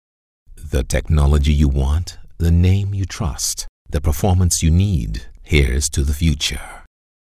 Englisch (Karibik)
Erzählung
Neumann U87
BaritonBassNiedrig
VertrauenswürdigUnternehmenAutorisierendSamtig